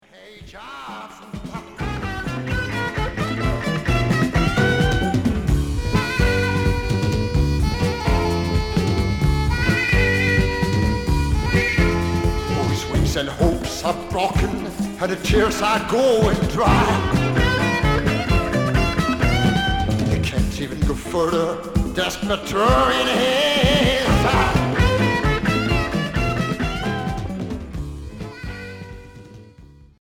Free rock Deuxième 45t retour à l'accueil